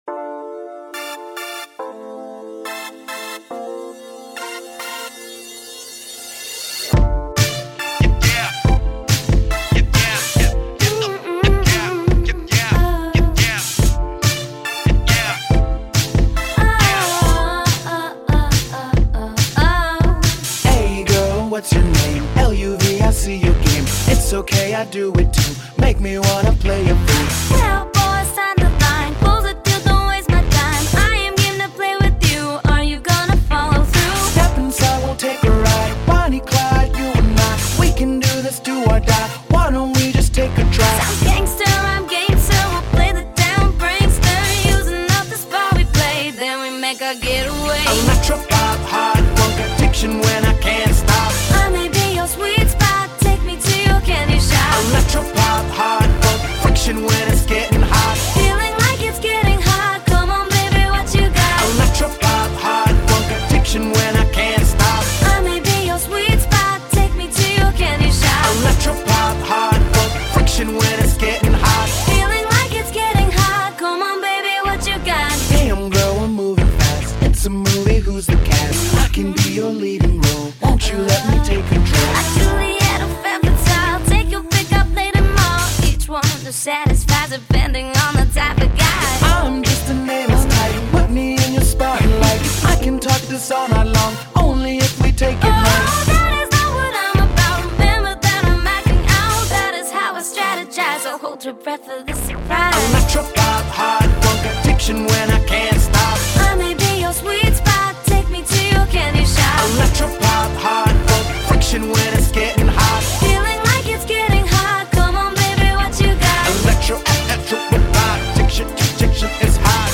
Electropop